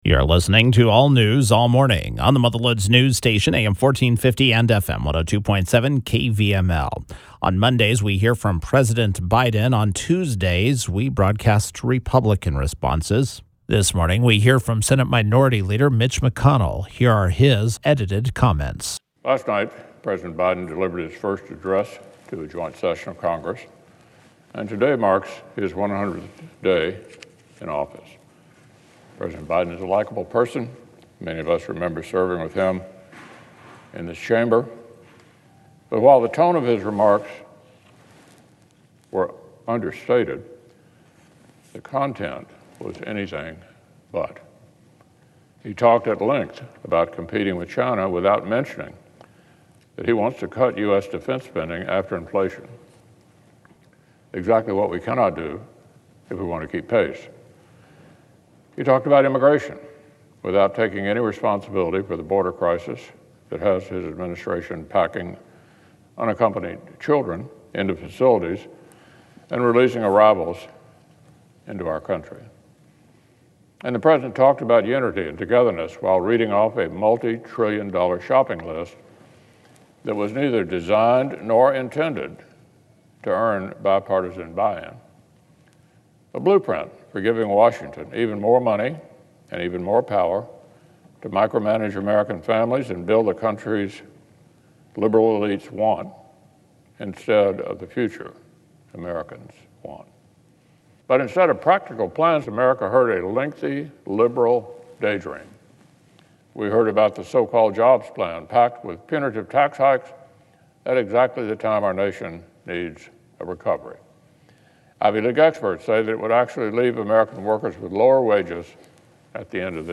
U.S. Senate Republican Leader Mitch McConnell (R-KY) delivered remarks on the Senate floor regarding President Biden’s Joint Session Address: